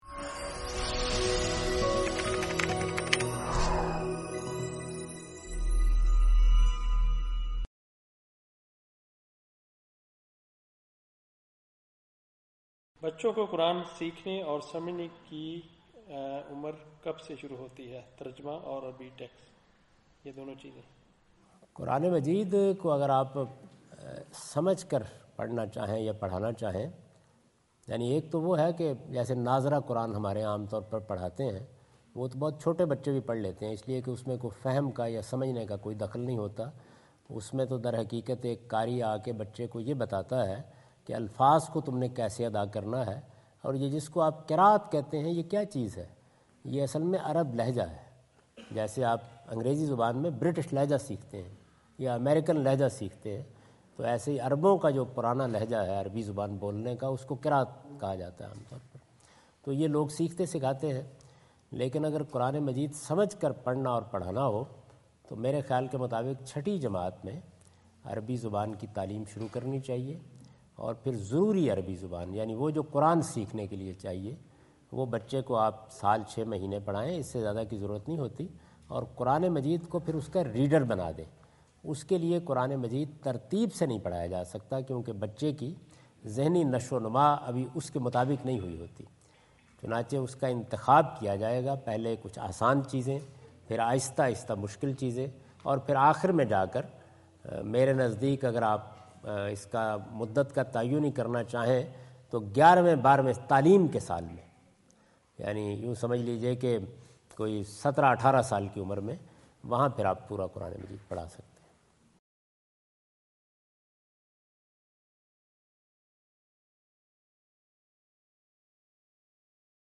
Category: English Subtitled / Questions_Answers /
Javed Ahmad Ghamidi answer the question about "Right Age for Children to Learn Quran" during his visit in Canberra Australia on 03rd October 2015.